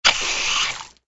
AA_squirt_seltzer_miss.ogg